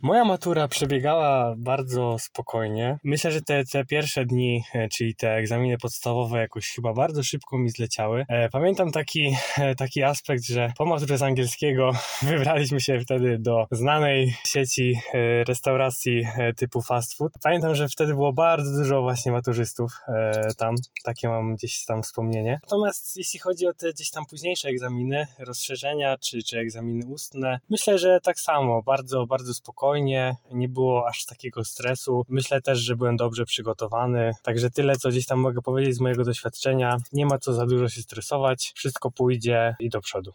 Zapytaliśmy studentów, jak oni wspominają swoje matury.